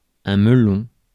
Ääntäminen
IPA : /ˈbəʊ.lə/
IPA : /ˈboʊ.lɚ/